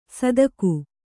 ♪ sadaku